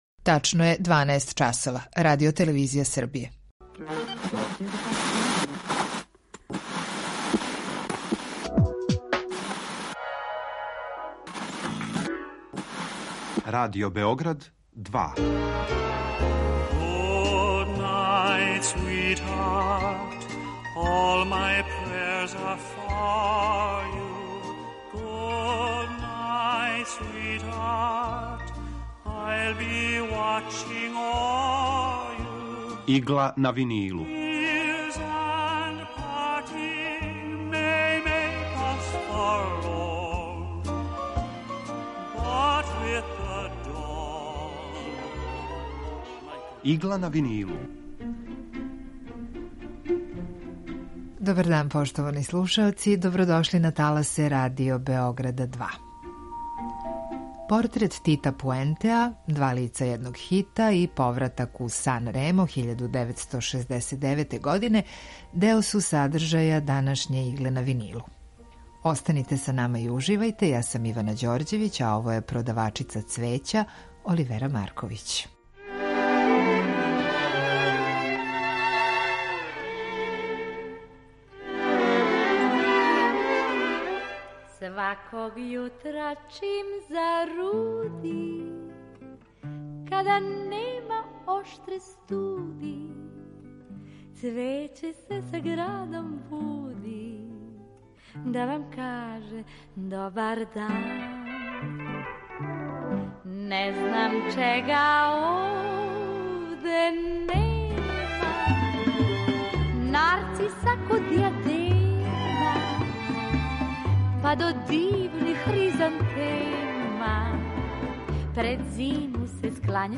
Евергрин музика.